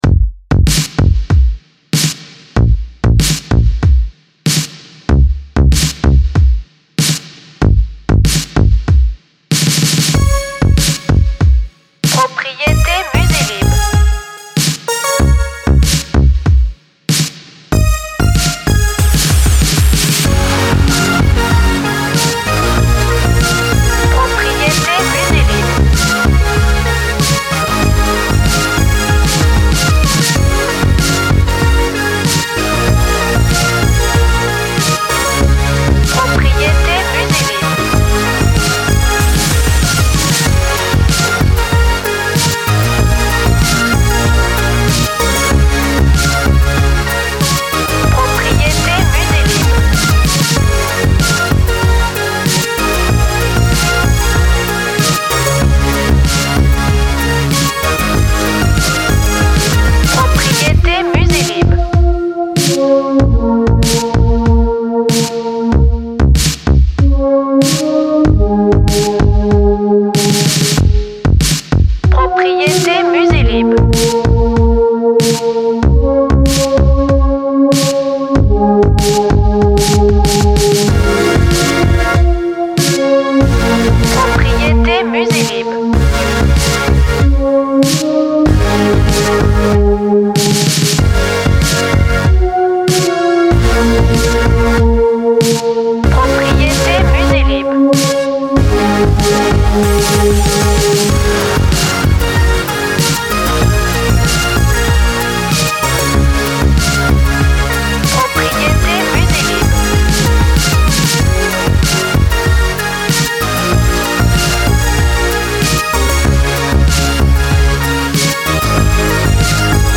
musique french touch